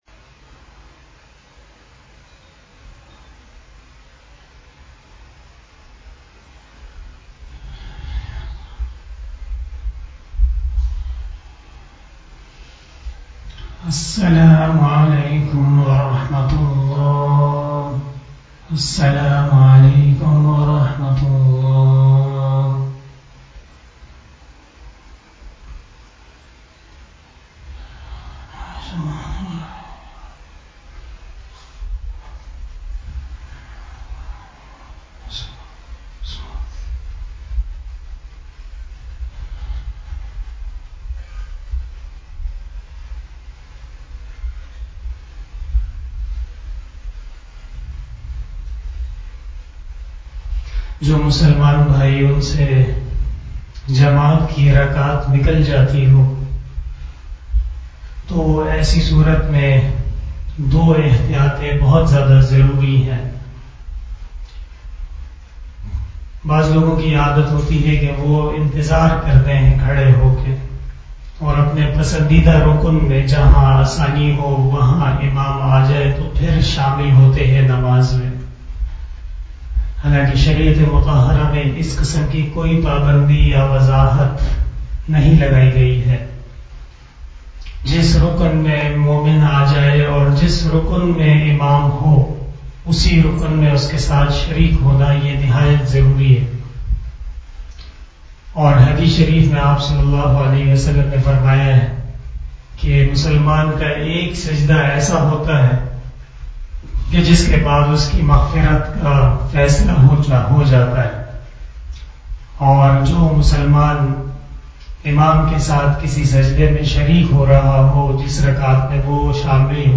After Asar Namaz Bayan